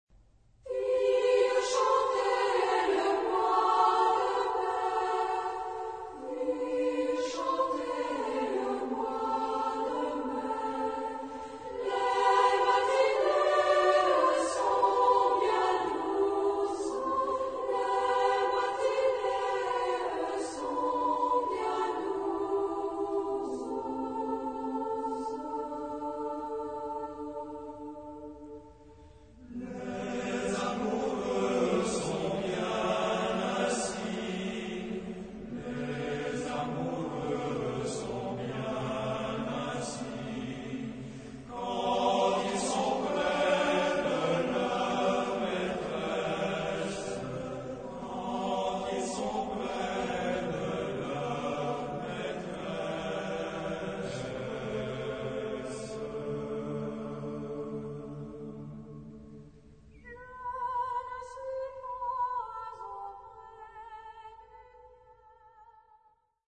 Genre-Style-Form: Partsong ; Folk music
Type of Choir: SATB  (4 mixed voices )
Soloist(s): Soprano (1)  (1 soloist(s))
Tonality: G major